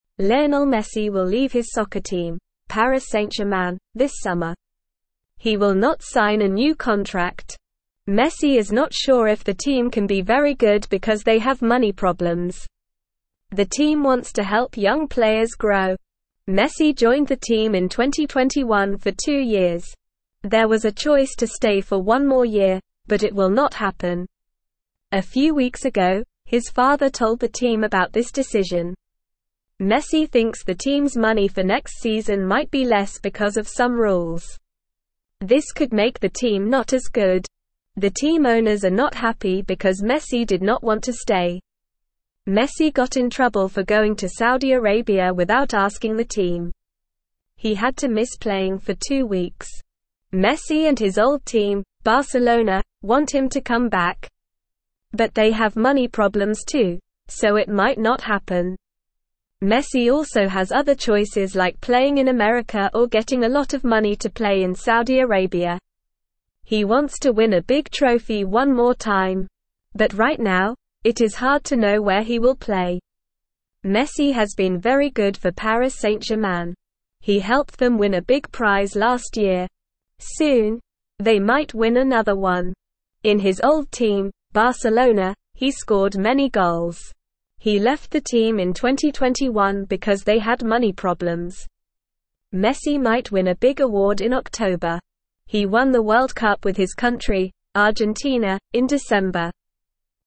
Normal
English-Newsroom-Beginner-NORMAL-Reading-Messi-Leaves-Soccer-Team-Soon.mp3